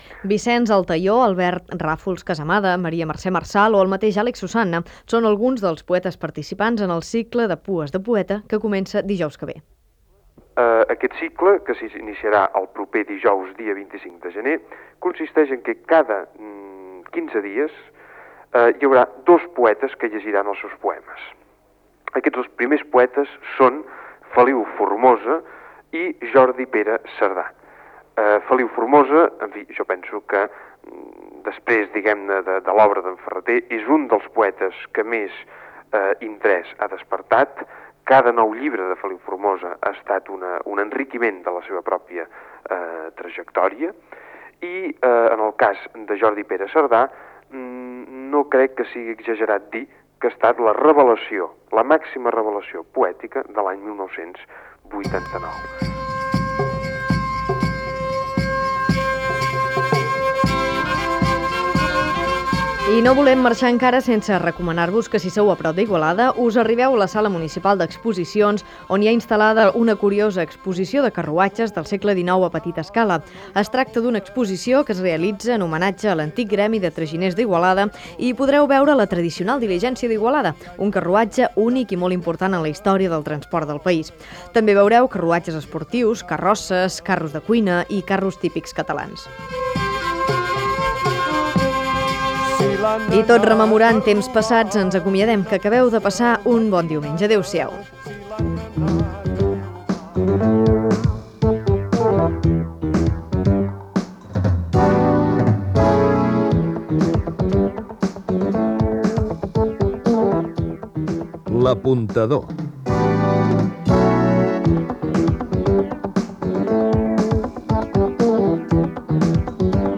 Vicenç Altaió parla del "Cicle pues de poetes", exposció de carruatges a Igualada, careta de sortida